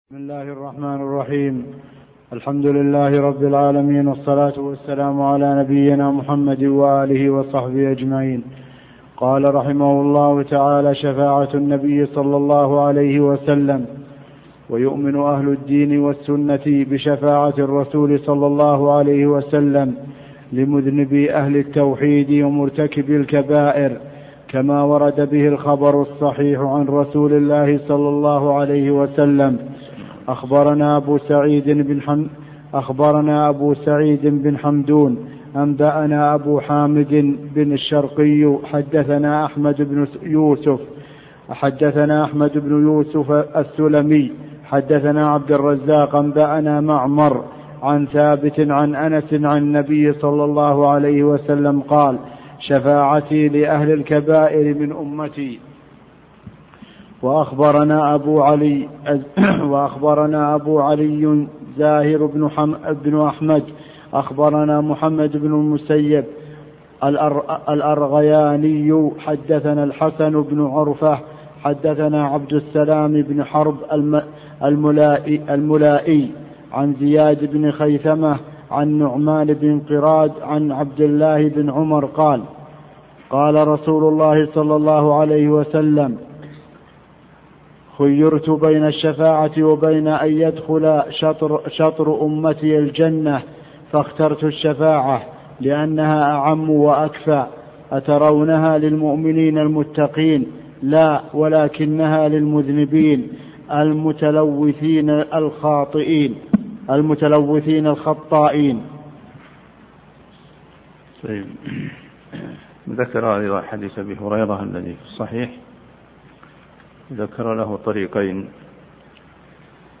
عنوان المادة الدرس (2) شرح عقيدة السلف أصحاب الحديث تاريخ التحميل الخميس 9 فبراير 2023 مـ حجم المادة 27.22 ميجا بايت عدد الزيارات 114 زيارة عدد مرات الحفظ 64 مرة إستماع المادة حفظ المادة اضف تعليقك أرسل لصديق